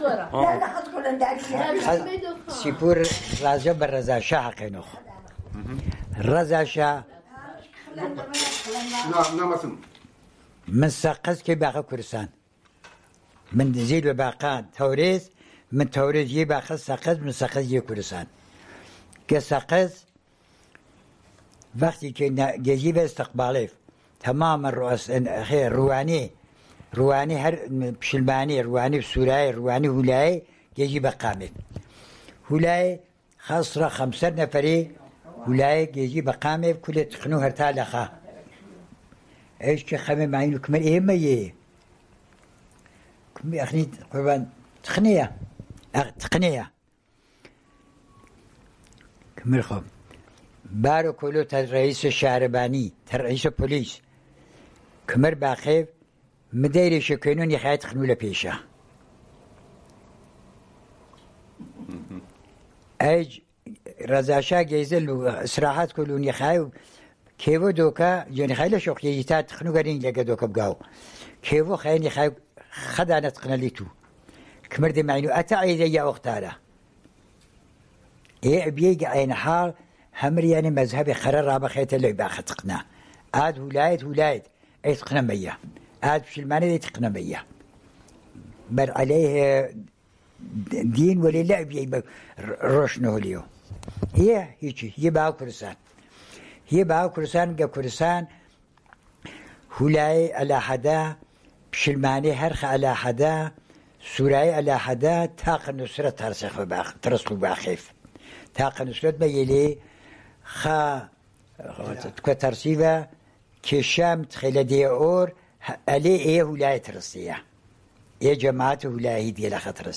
Sanandaj, Jewish: An Ode to our Language